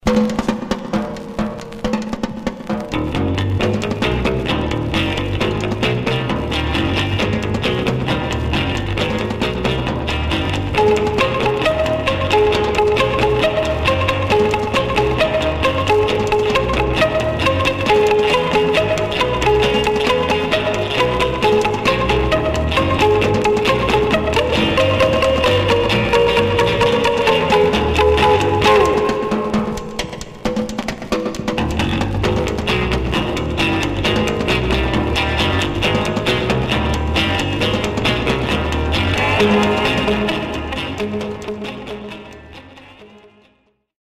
Some surface noise/wear
Mono
R & R Instrumental Condition